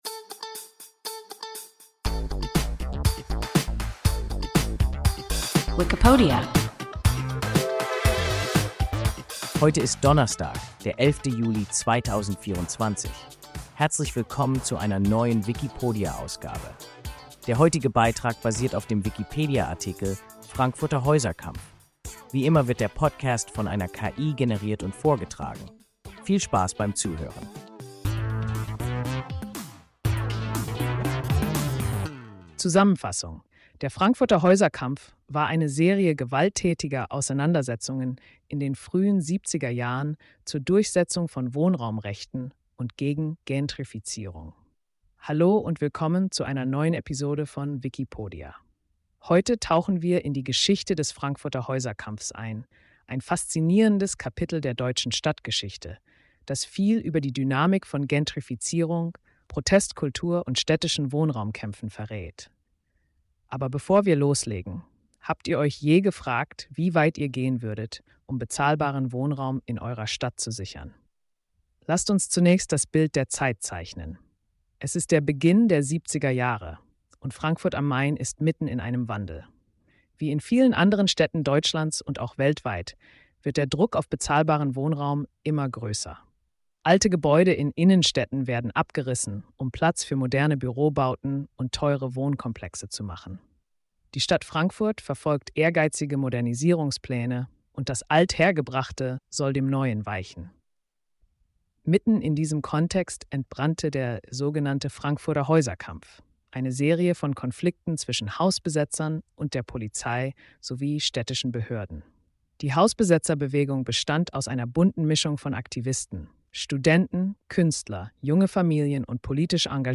Frankfurter Häuserkampf – WIKIPODIA – ein KI Podcast